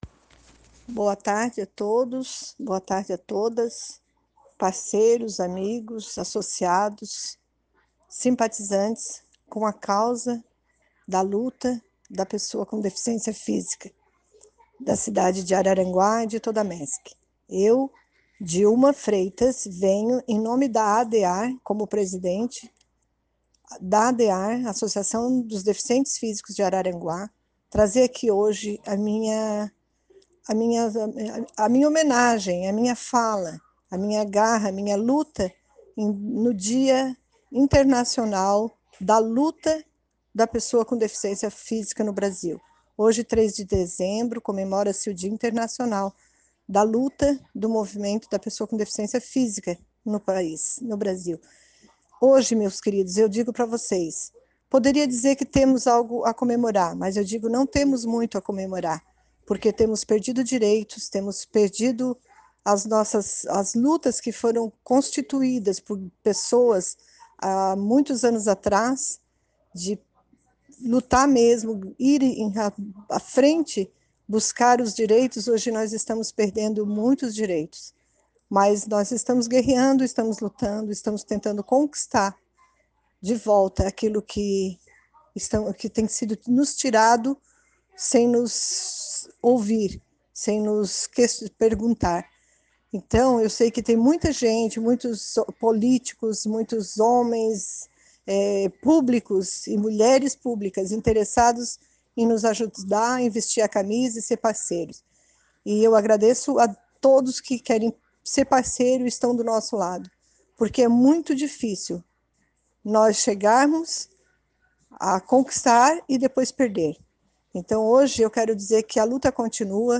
Adear agradece apoiadores em áudio no Dia Internacional da Pessoa com Deficiência - Post TV